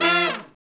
Index of /90_sSampleCDs/Roland LCDP06 Brass Sections/BRS_Section FX/BRS_R&R Falls